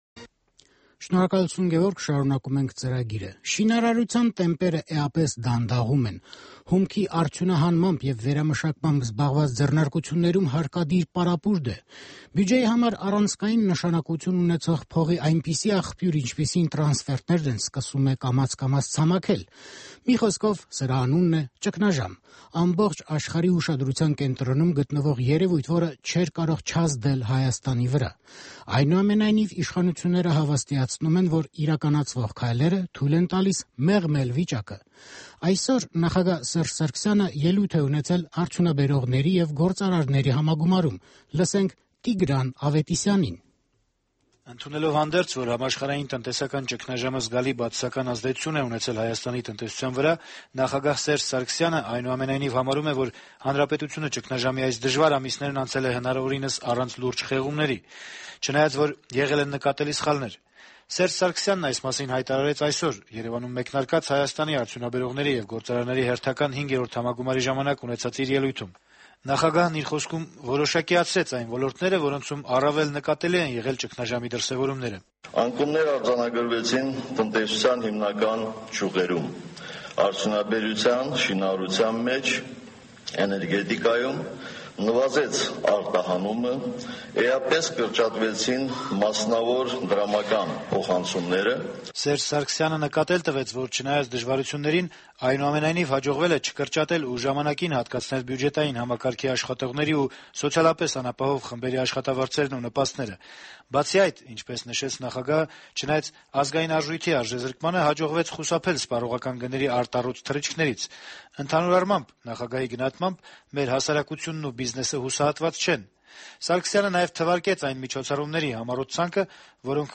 Սերժ Սարգսյանի ելույթը